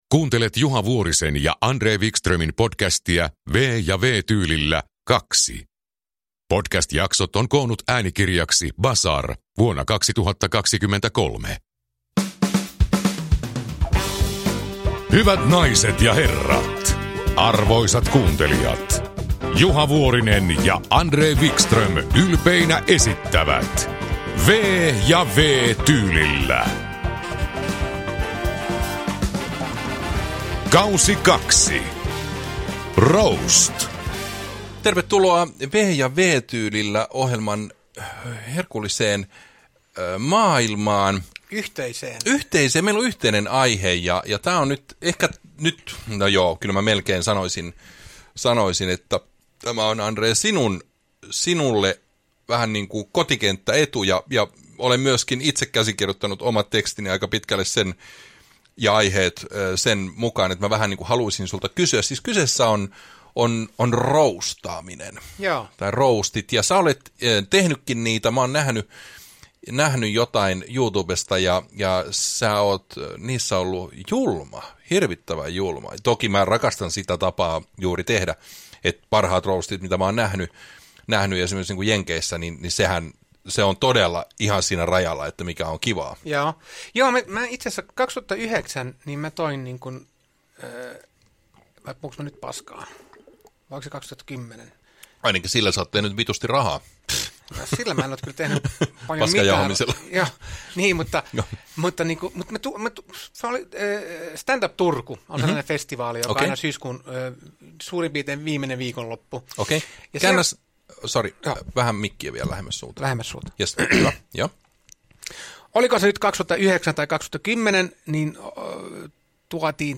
Uppläsare: Juha Vuorinen, André Wickström